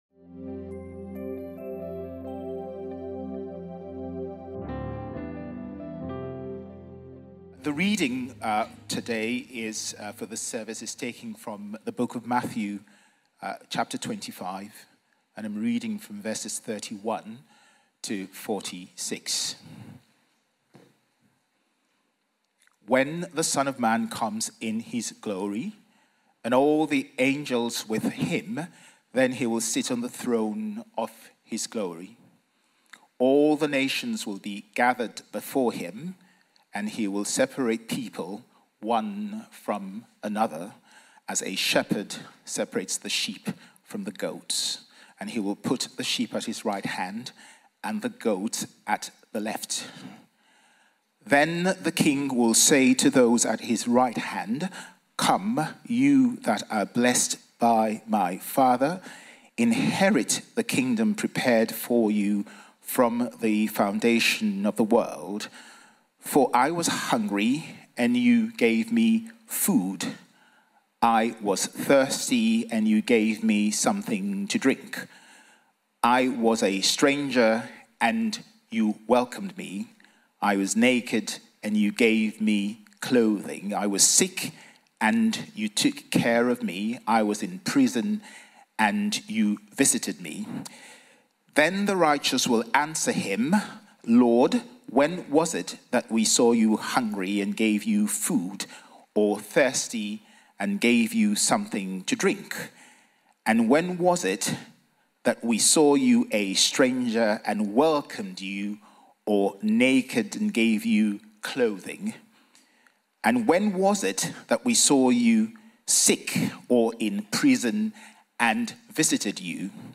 Responsive Conference
_Sermon Series